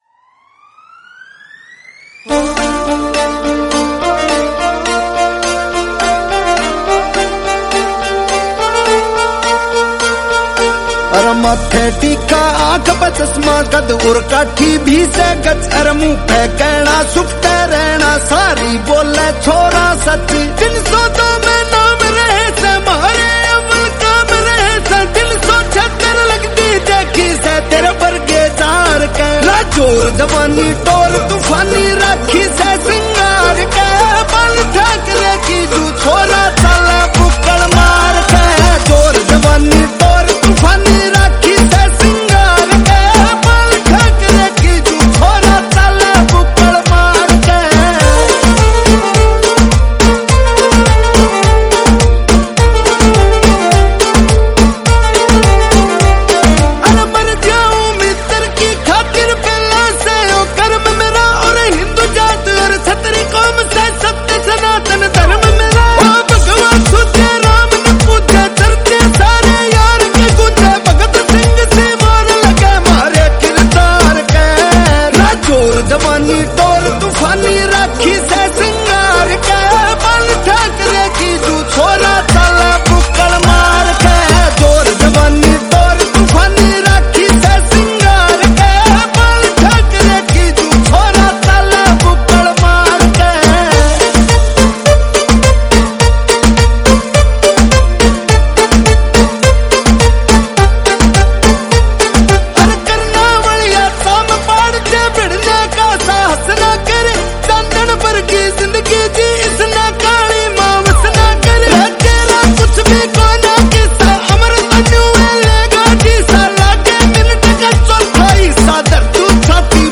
Category: Haryanvi